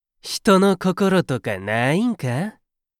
パロディ系ボイス素材　4